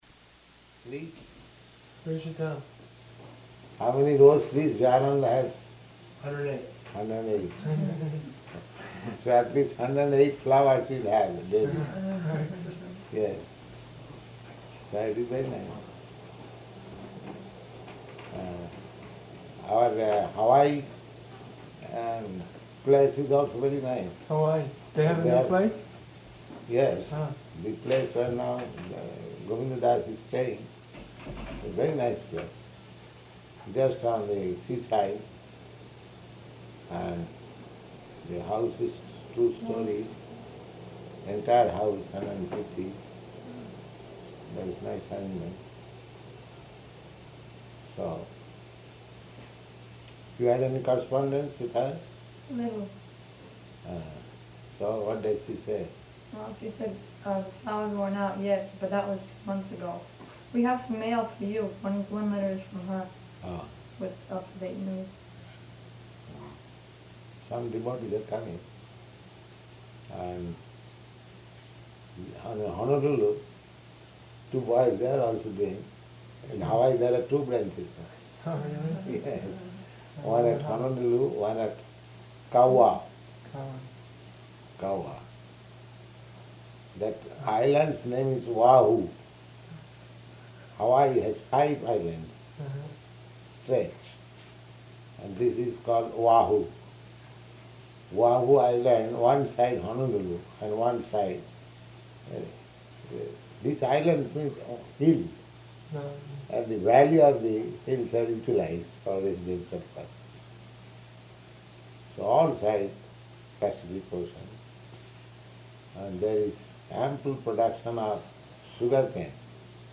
Room Conversation
Room Conversation --:-- --:-- Type: Conversation Dated: April 24th 1969 Location: Boston Audio file: 690424R1-BOSTON.mp3 Prabhupāda: ...tree?